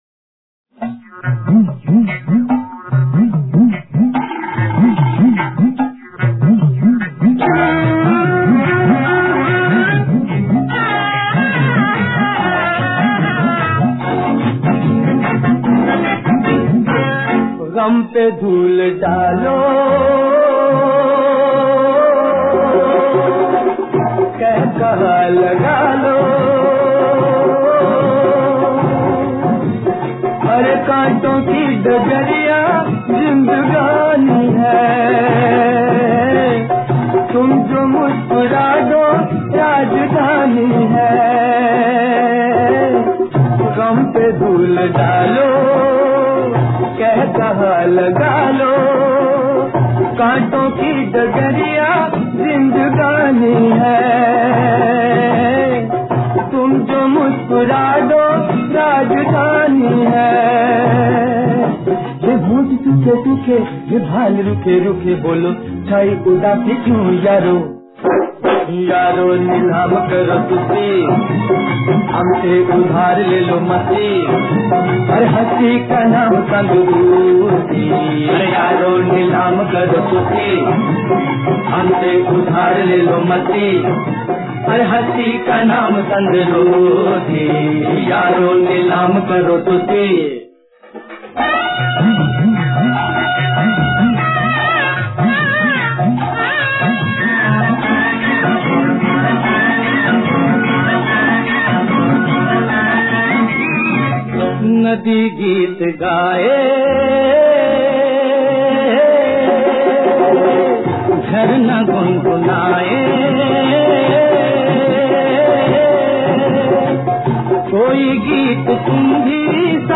First two lines would be based on folk,
The third line will be based on classical,
The fourth line on pop,
The fifth line on qawwali”